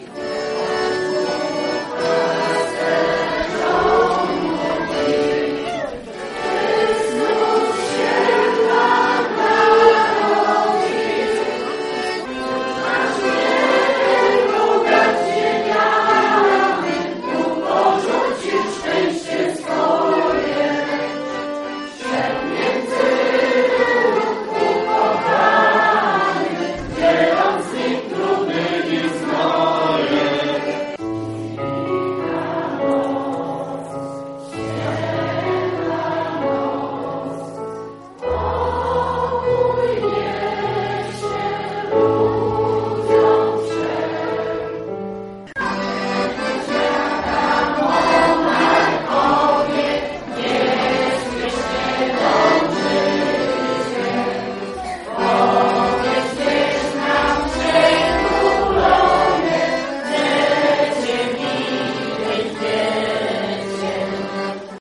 Przy akompaniamencie pianina i akordeonu tradycyjne polskie kolędy zaśpiewały licznie zgromadzone rodziny.
1_koledy.mp3